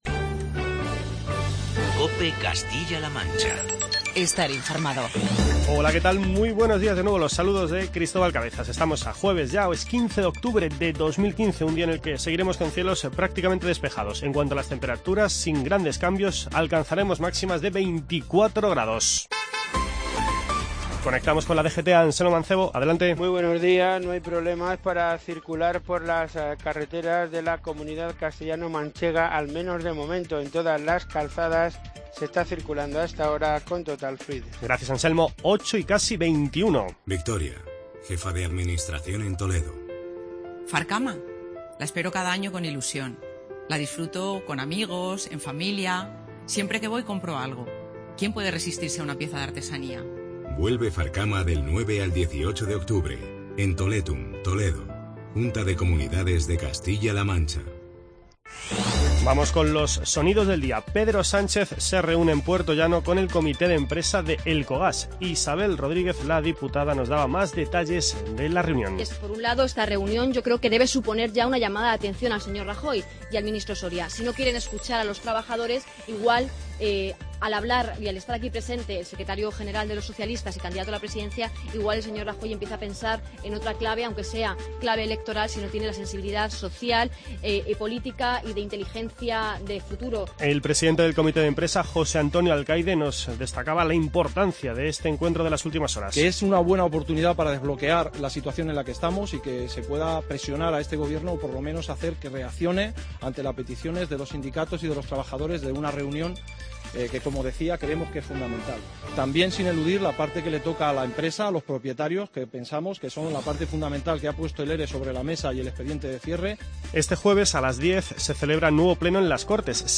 Informativo regional y provincial
Repasamos los sonidos más destacados de las últimas horas.